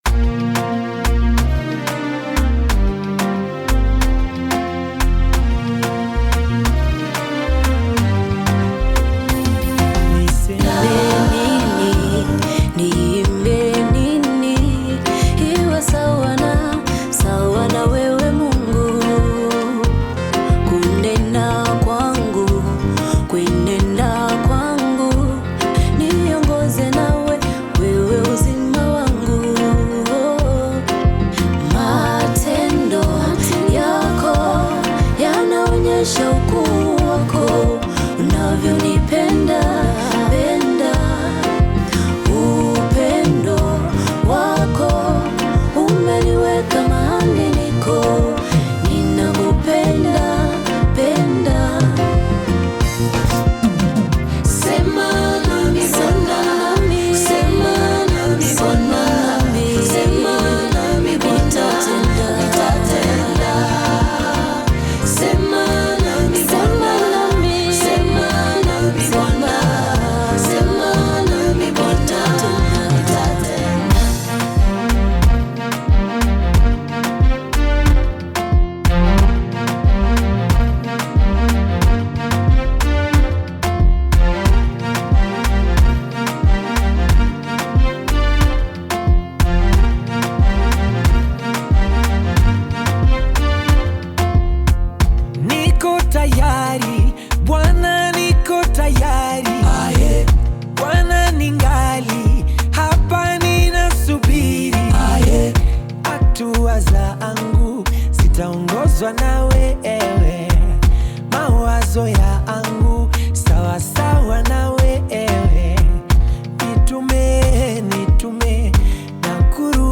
gospel song
African Music